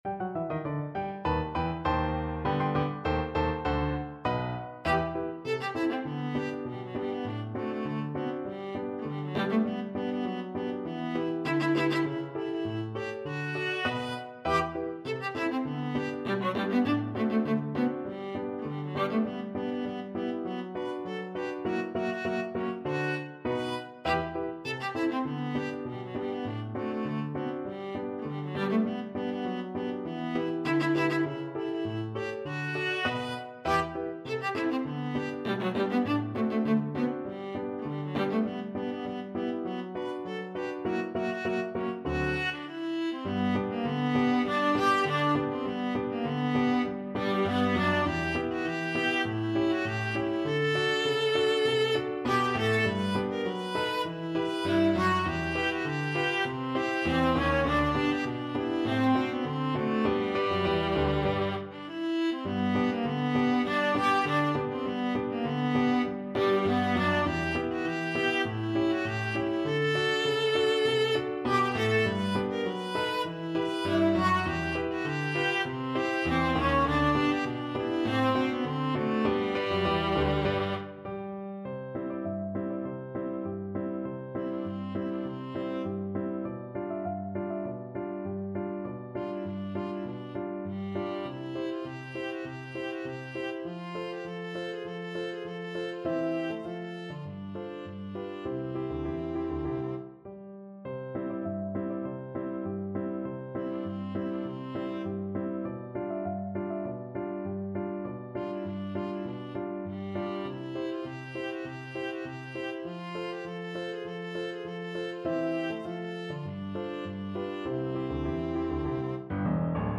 Viola
G major (Sounding Pitch) (View more G major Music for Viola )
2/2 (View more 2/2 Music)
F#4-E6
Classical (View more Classical Viola Music)